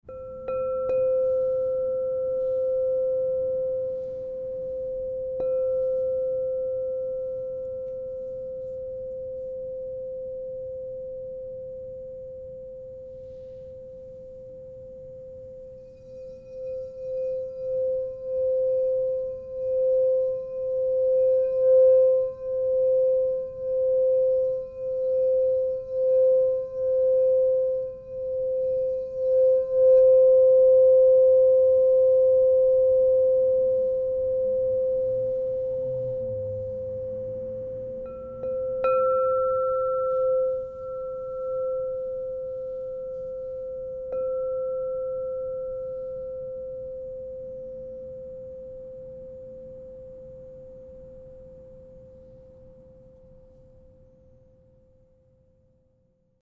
Crystal Tones® Indigo 6 Inch C True Tone Singing Bowl
Discover the profound resonance of the Crystal Tones® Indigo 6 inch C True Tone Singing Bowl, resonating at C -10 to inspire tranquility and spiritual clarity.
Its precise tuning ensures rich, resonant tones that harmonize with other instruments, making it an exceptional tool for sound healing, meditation, and personal transformation.
Transform your practice with 6″ Crystal Tones® True Tone alchemy singing bowl made with Indigo in the key of C -10.
432Hz (-), 440Hz (TrueTone)